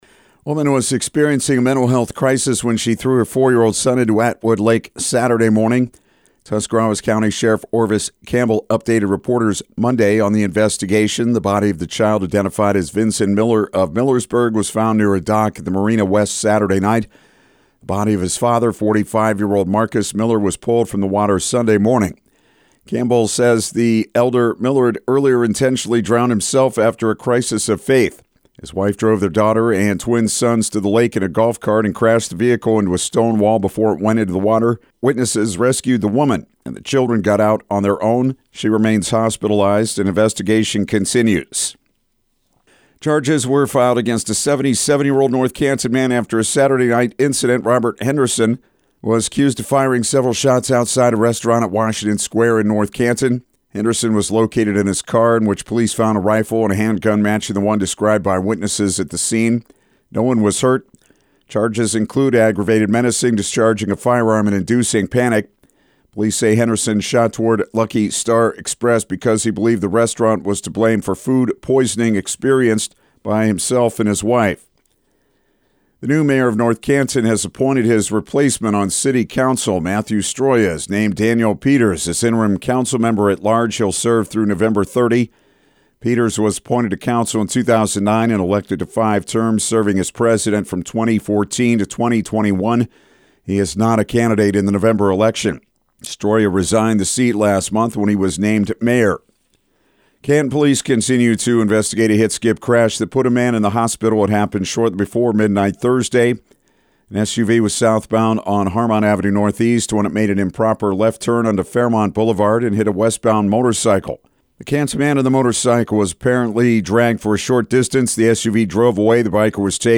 6am-news-26.mp3